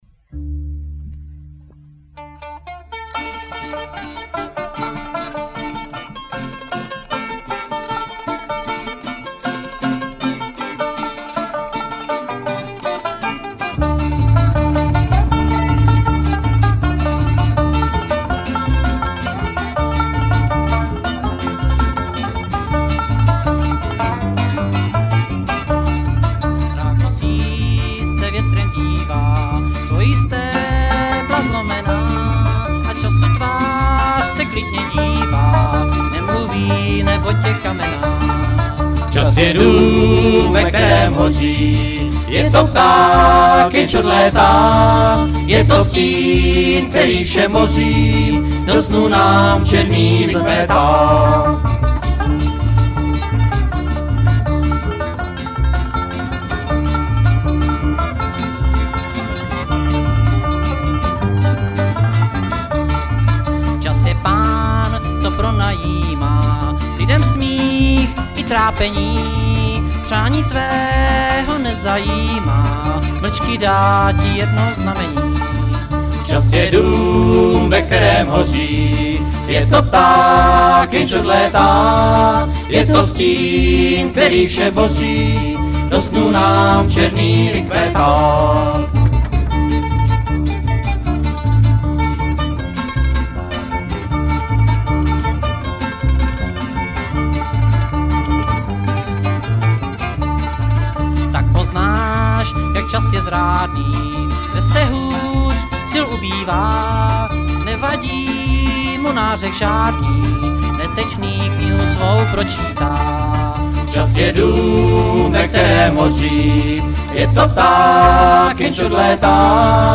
Nahrávka pochází z kotoučáku pana zvukaře.